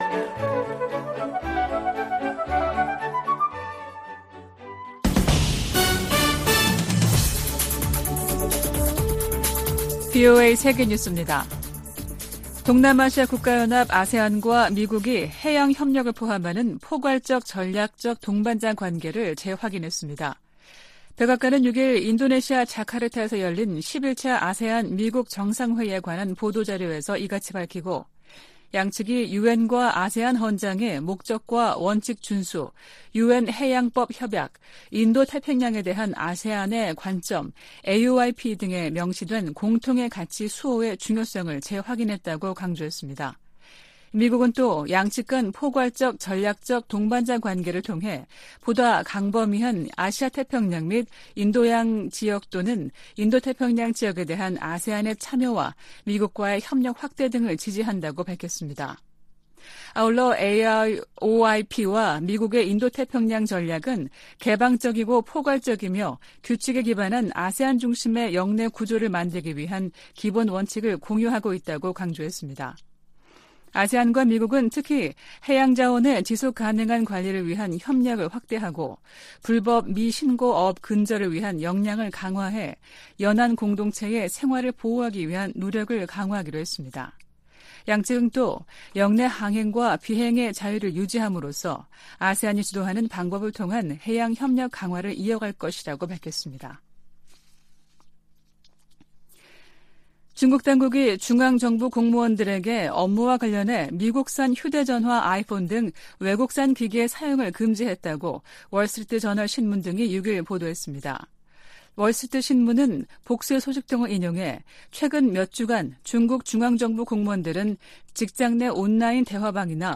VOA 한국어 아침 뉴스 프로그램 '워싱턴 뉴스 광장' 2023년 9월 8일 방송입니다. 존 커비 백악관 국가안보회의(NSC) 전략소통조정관은 북한과 러시아 간 무기거래 협상을 면밀히 주시할 것이라고 경고했습니다. 동아시아정상회의(EAS)에 참석한 윤석열 한국 대통령은 대북 제재 준수에 유엔 안보리 상임이사국의 책임이 더 무겁다고 말했습니다. 유럽연합(EU)은 북러 정상회담 가능성에 관해 러시아의 절박한 처지를 보여줄 뿐이라고 지적했습니다.